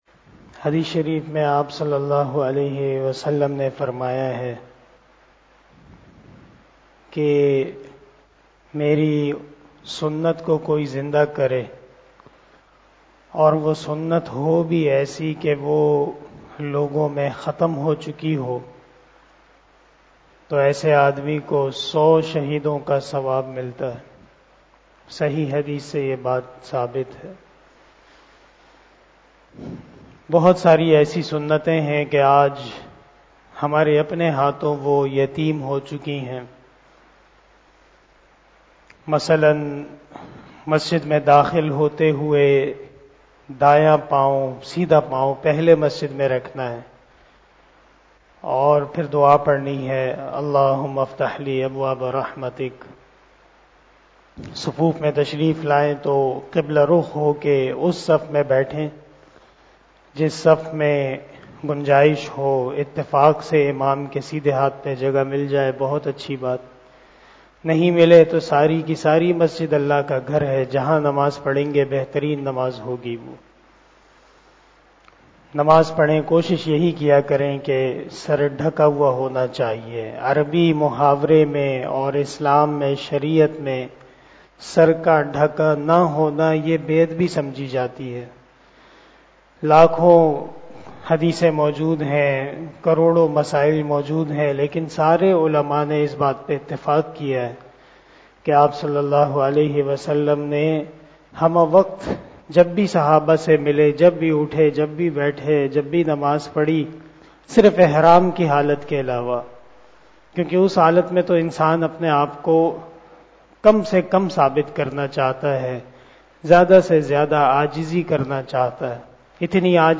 020 After Asar Namaz Bayan 28 March 2022 ( Shaban 24 1443HJ) Monday